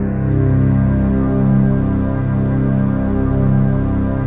DM PAD2-38.wav